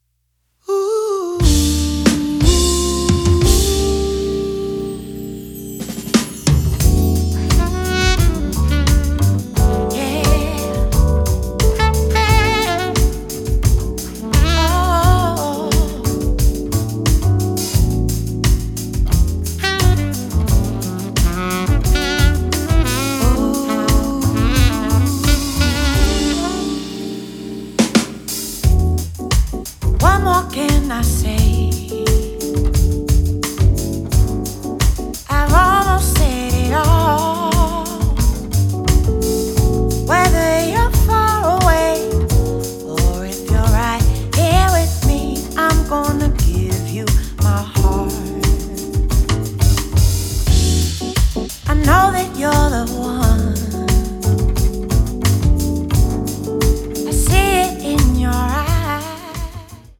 double bass
piano and keyboards
drums
alto sax and percussions
Recorded at Sorriso Studio